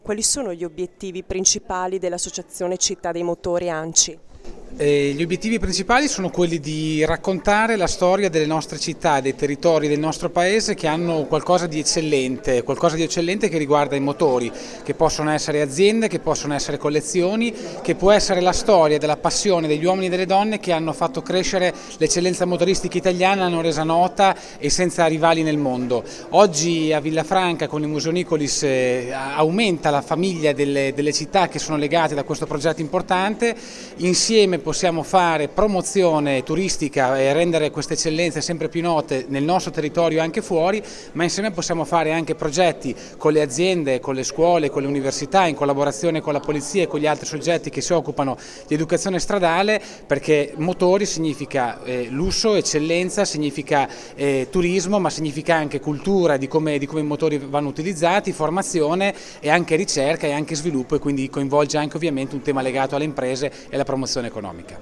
ha intervistato per noi: